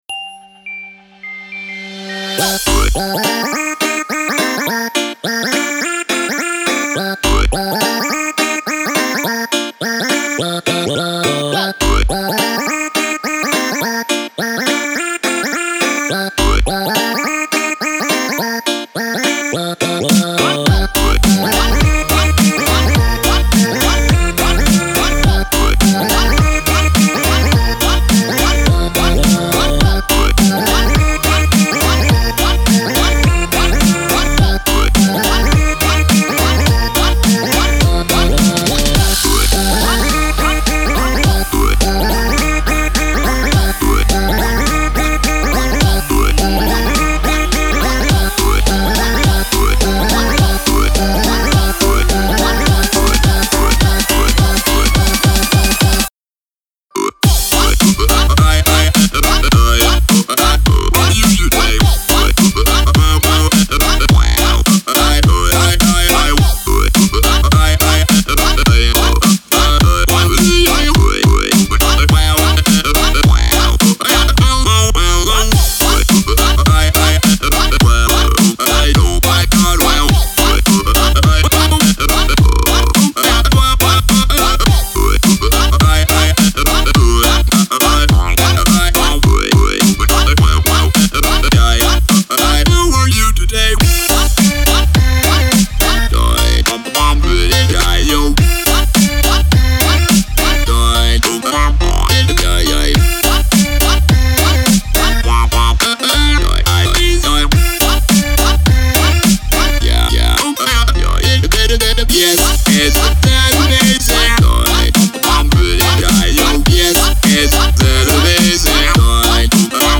omfg_prikolnie_pesni___prosto_veselaja_muzika_4_.mp3